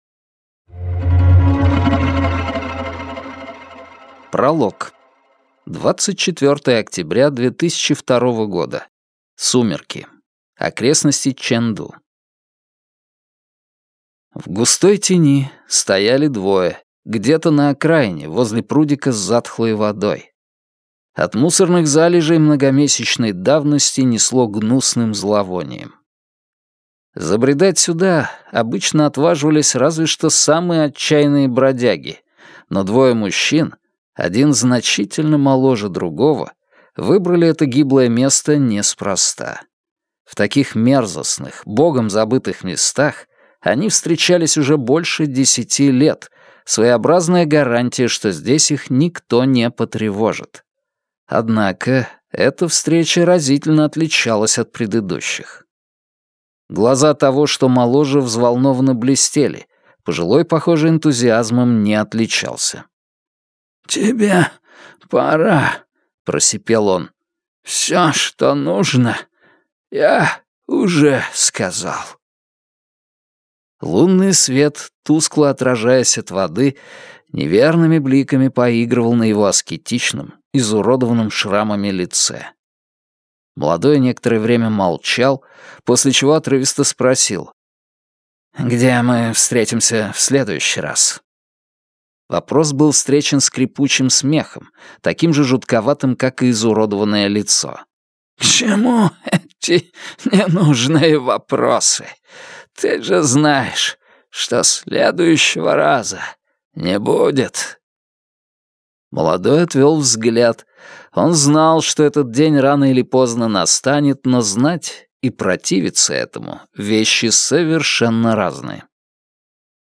Аудиокнига Знаки судьбы | Библиотека аудиокниг
Прослушать и бесплатно скачать фрагмент аудиокниги